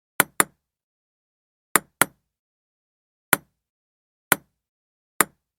Ping Pong Ball Double Hits
SFX
Ping Pong Ball Double Hits is a free sfx sound effect available for download in MP3 format.
yt_DF9LxW1qZg4_ping_pong_ball_double_hits.mp3